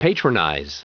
Prononciation du mot patronize en anglais (fichier audio)
Prononciation du mot : patronize